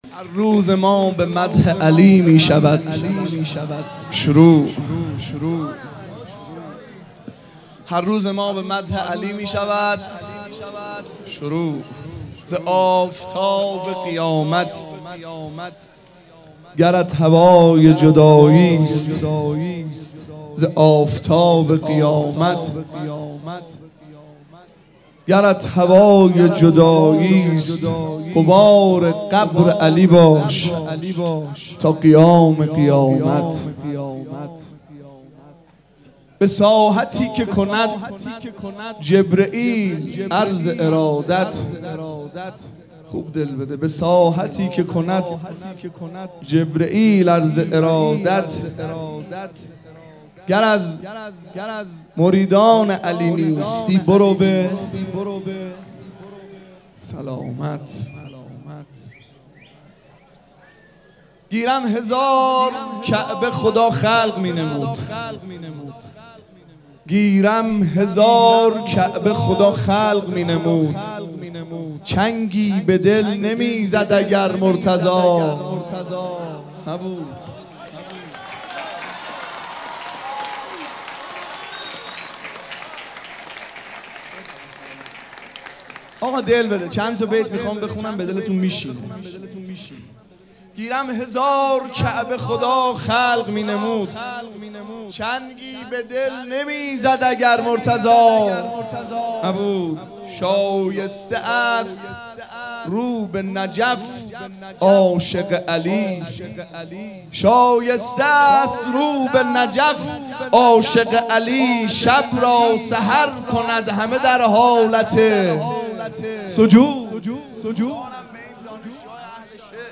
مداحی به سبک مدح اجرا شده است.